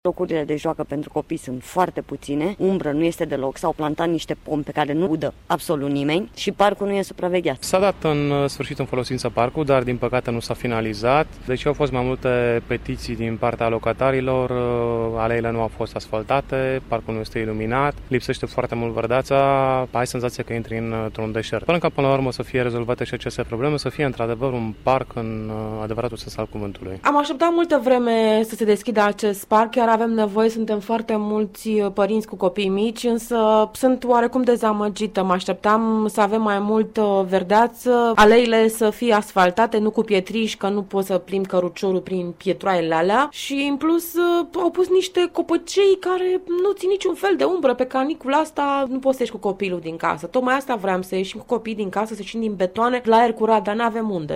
vox_parc.mp3